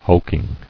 [hulk·ing]